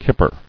[kip·per]